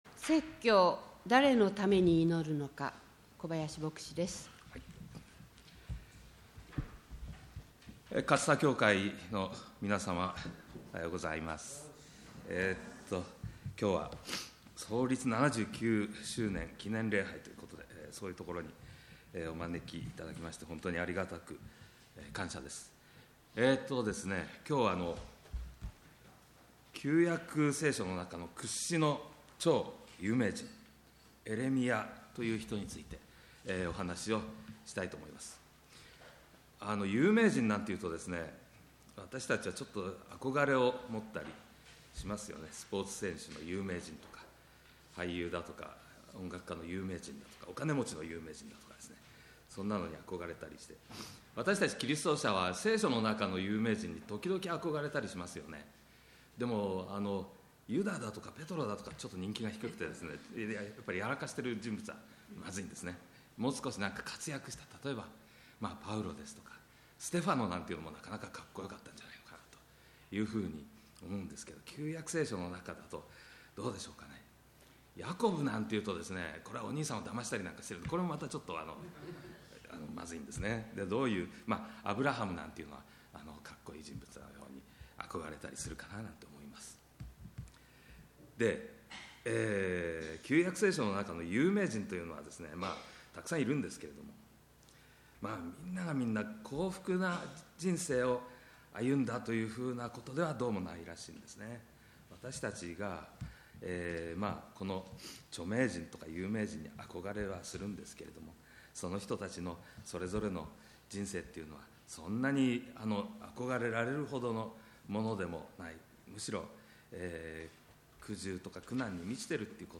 創立記念礼拝の説教です。